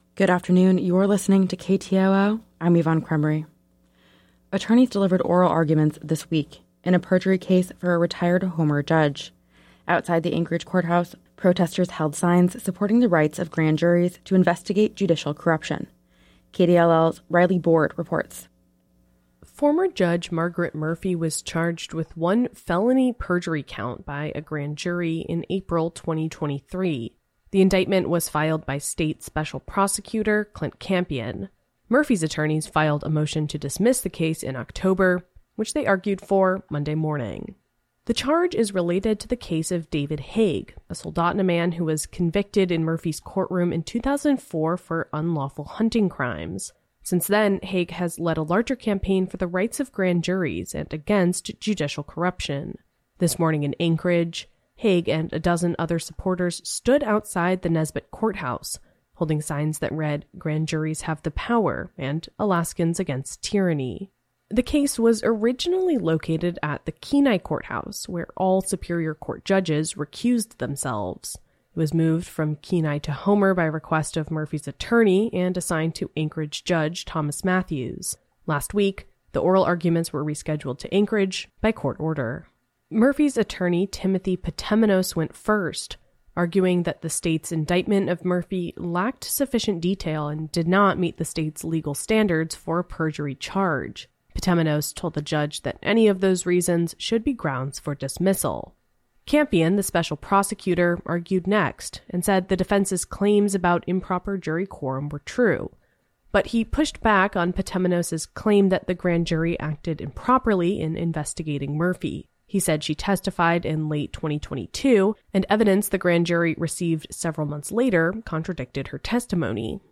Newscast – Thursday. Jan. 11 2024